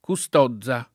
kuSt0zza] top. (Ven.) e cogn. — antiq. anche sul posto la pn.